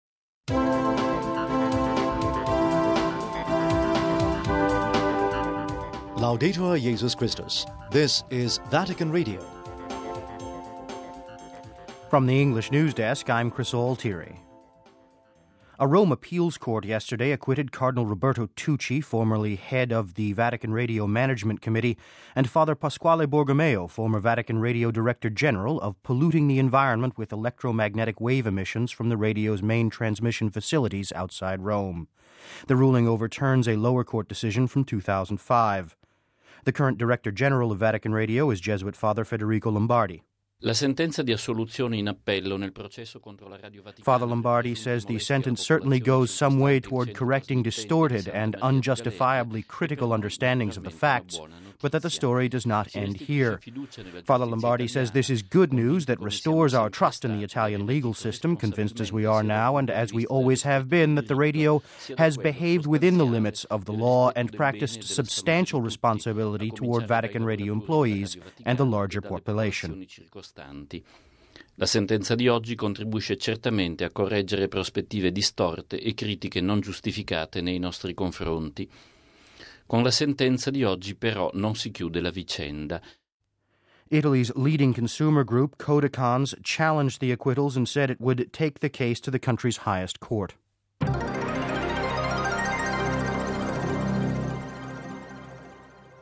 reports on Vatican Radio's reaction to an Italian appeals court decision